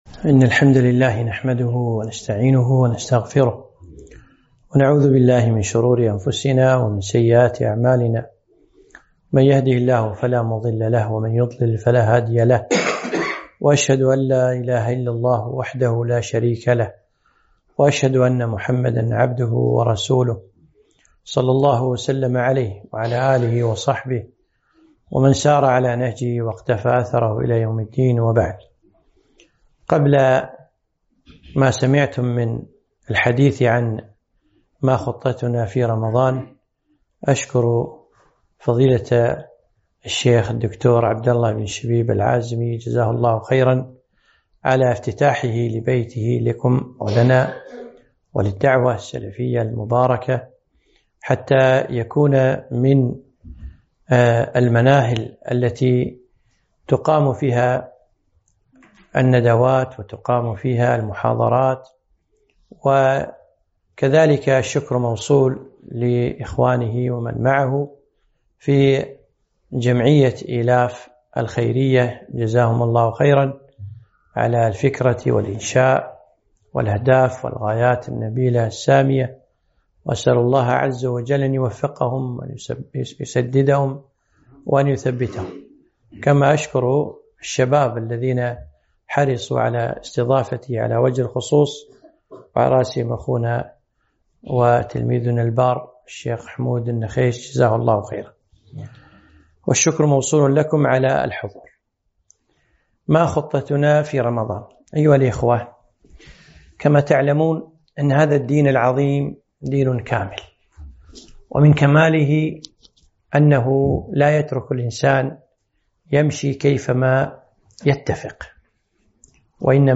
محاضرة - ما خطتنا في رمضان؟